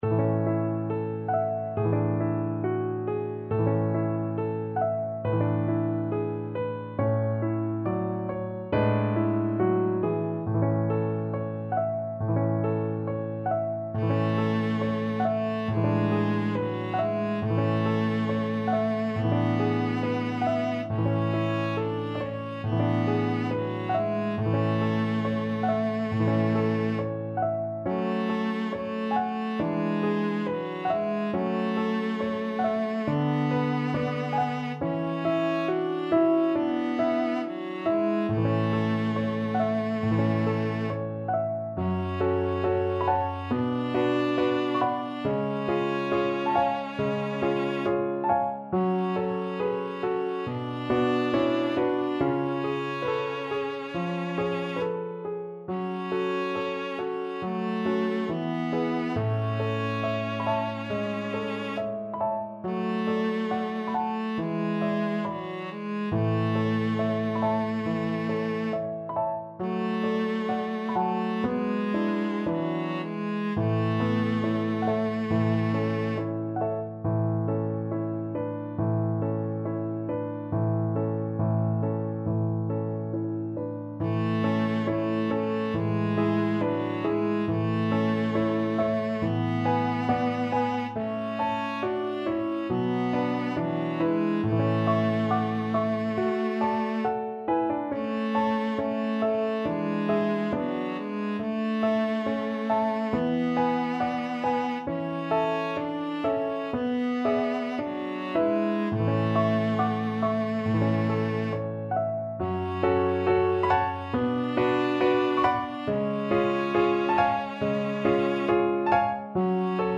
Traditional Music of unknown author.
~ = 69 Andante tranquillo